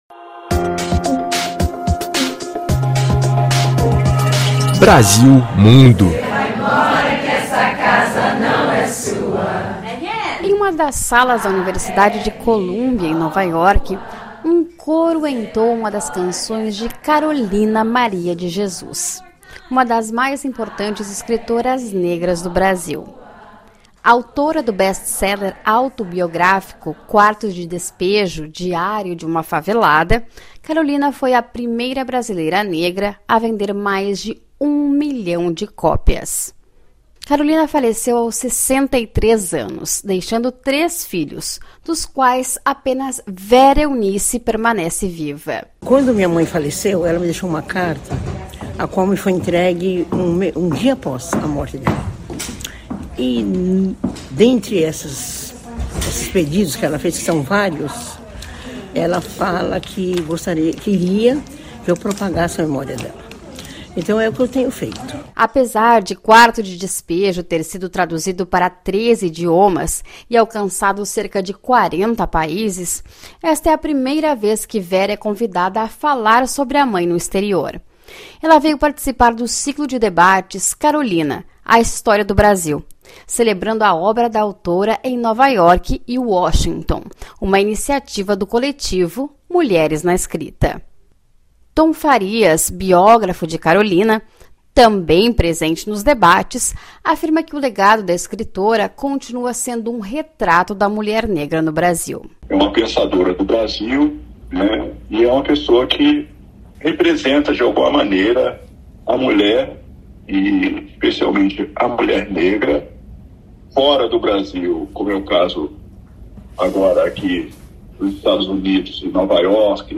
Em uma das salas da Universidade de Columbia, em Nova York, um coro entoa uma das canções de Carolina Maria de Jesus, uma das mais importantes escritoras negras do Brasil.